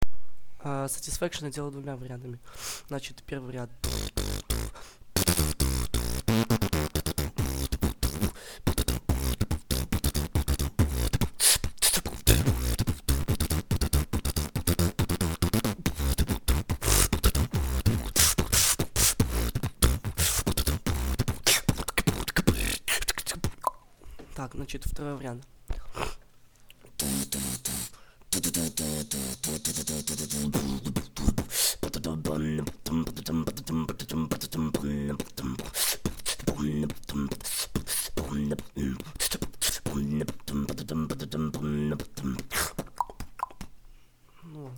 Видеоуроки по "каверам"